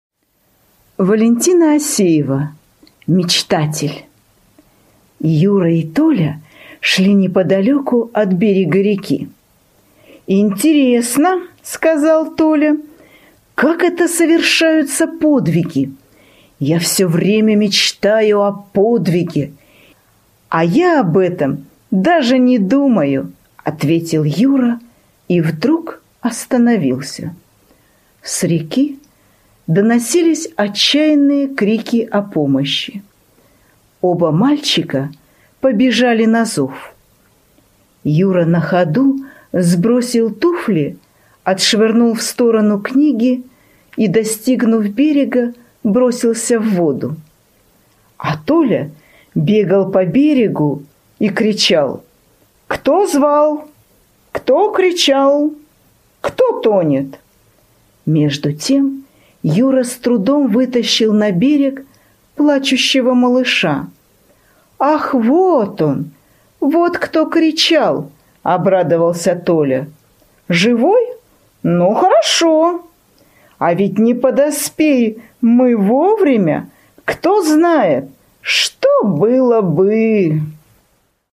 На данной странице вы можете слушать онлайн бесплатно и скачать аудиокнигу "Мечтатель" писателя Валентина Осеева.